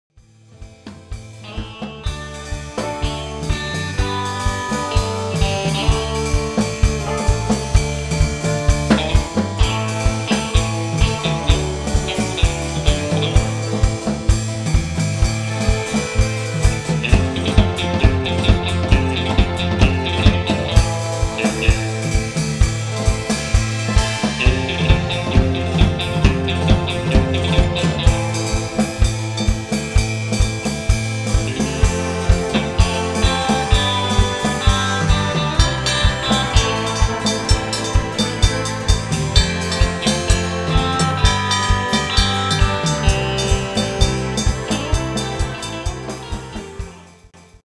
Live opnames